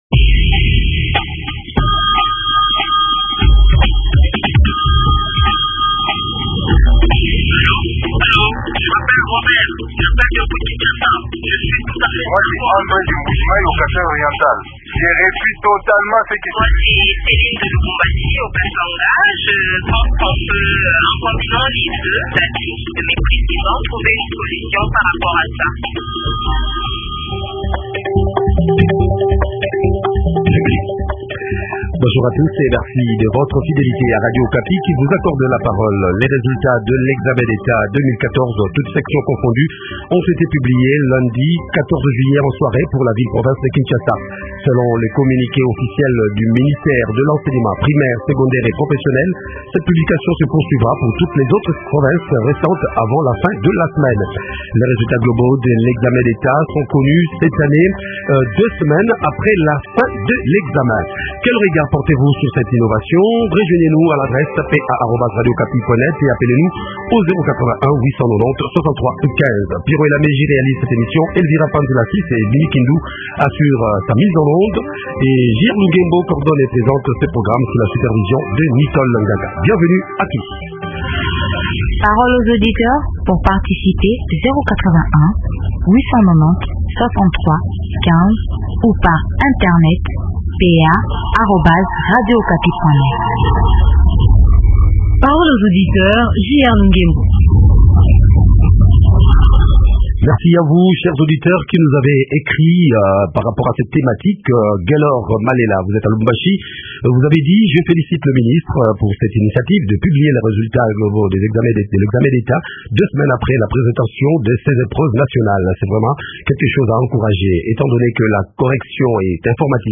Invité: Maker Mwangu, ministre de l’Enseignement primaire, secondaire et professionnel.